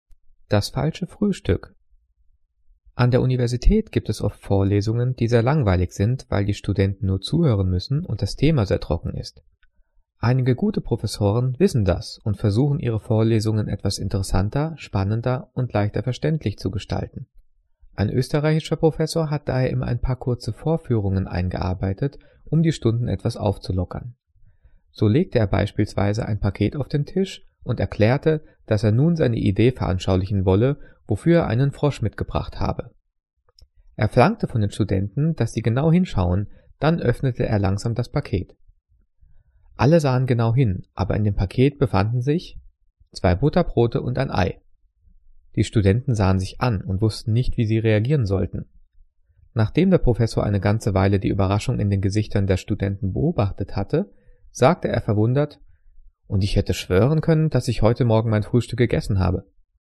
Diktat: "Das falsche Frühstück" - 5./6. Klasse - Zeichensetzung
Gelesen:
gelesen-das-falsche-fruehstueck.mp3